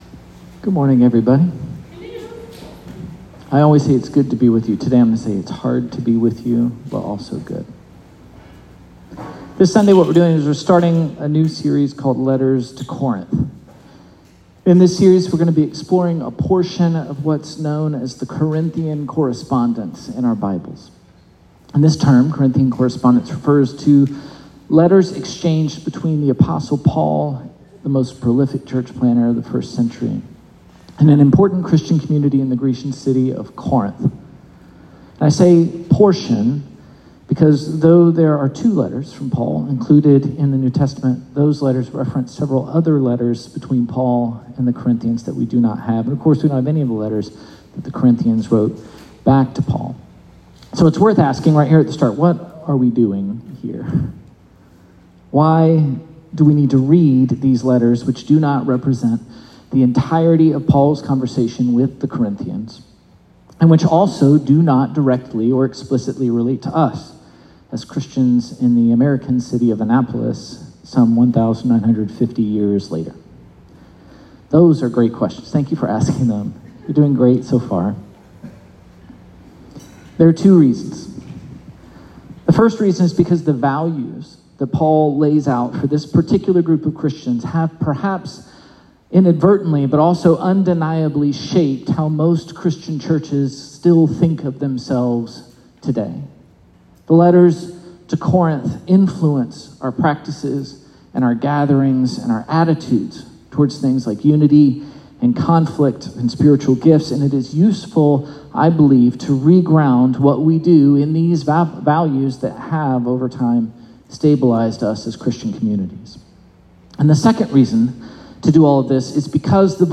REFLECTION/DISCUSSION QUESTIONS: The sermon discusses how defending ourselves in disagreements can widen the gap instead of bringing us together. How can humility help to address this problem?